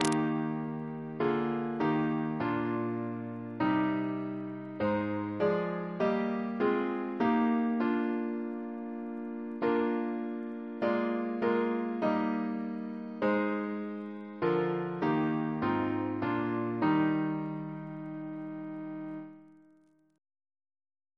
CCP: Chant sampler
Double chant in E♭ Composer: Matthew Camidge (1758-1844), Organist of York Minster Reference psalters: ACB: 20; RSCM: 28